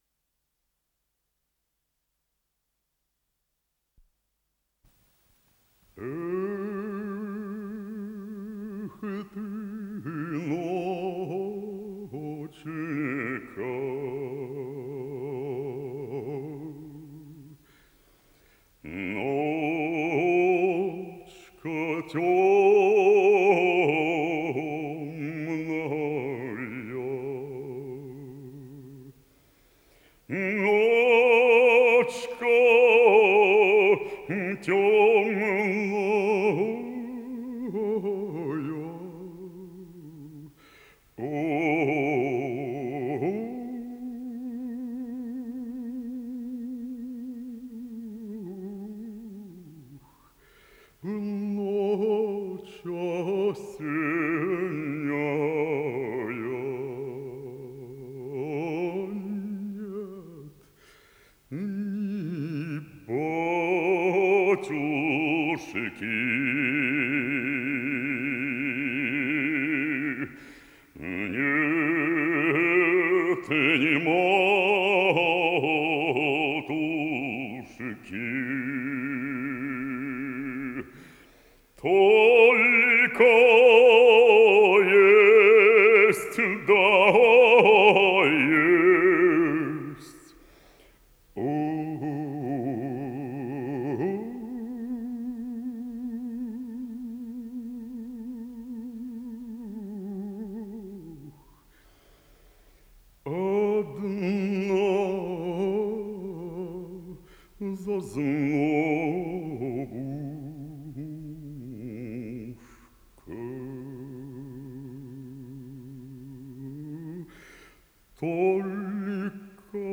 с профессиональной магнитной ленты
КомпозиторыРусская народная песня
ИсполнителиБорис Штоколов - бас
ВариантДубль моно